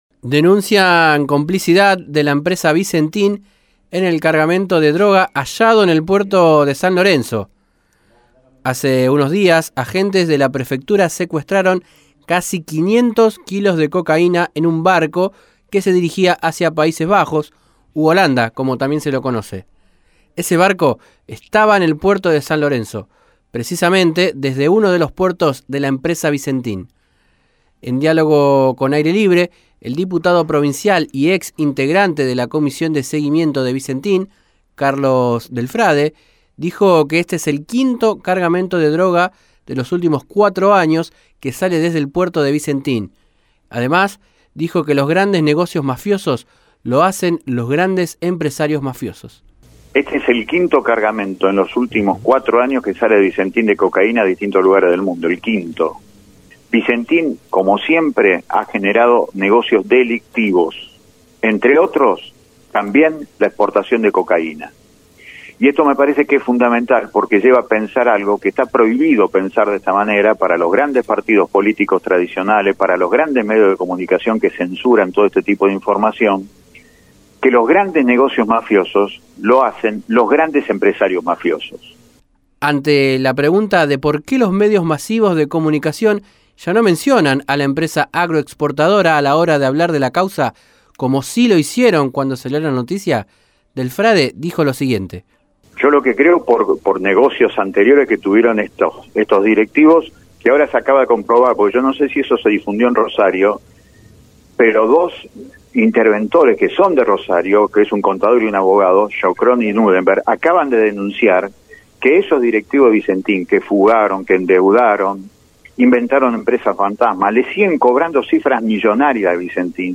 En diálogo con Aire Libre, el diputado provincial y ex integrante de la Comisión de seguimiento de Vicentin, Carlos Del Frade, dijo que “este es el quinto cargamento de droga de los últimos cuatro años que sale” desde el puerto de Vicentin que “como siempre ha generado negocios delictivos, entre otros también la exportación de cocaína. Los grandes negocios mafiosos los hacen los grandes empresarios mafiosos”, agregó.